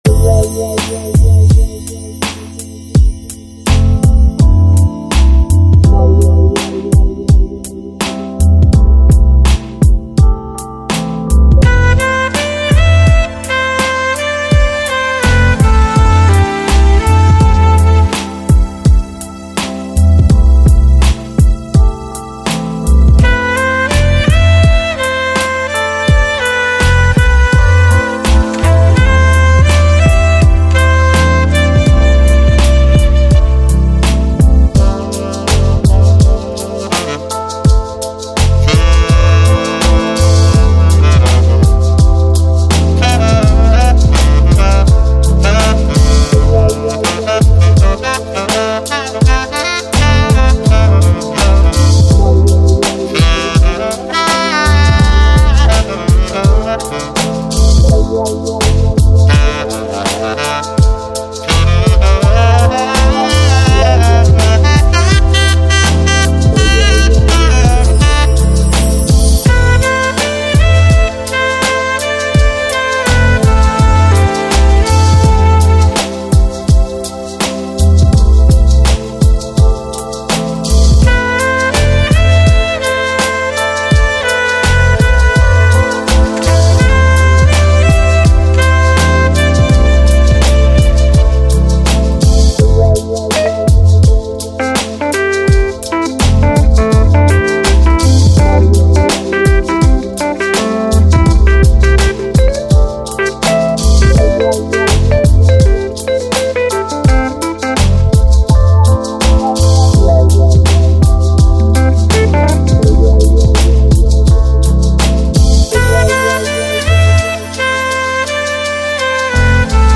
Жанр: Lounge, Chill Out, Smooth Jazz